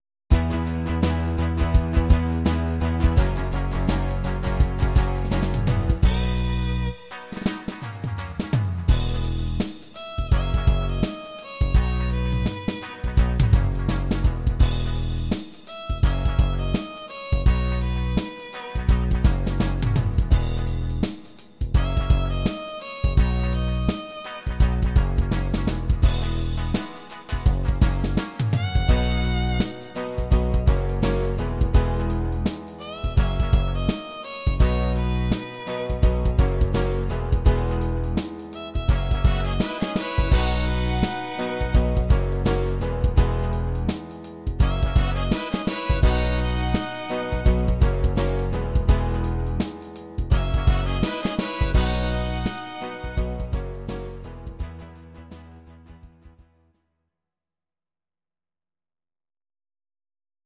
These are MP3 versions of our MIDI file catalogue.
Please note: no vocals and no karaoke included.
Your-Mix: Medleys (1041)